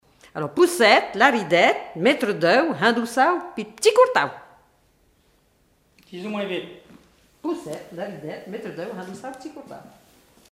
formulette enfantine : jeu des doigts
Répertoire de chansons traditionnelles et populaires
Pièce musicale inédite